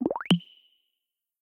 Synth-Appear-01.m4a